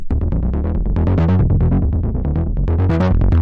描述：合成器
Tag: 合成器